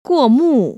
[guò//mù] 꾸오무  ▶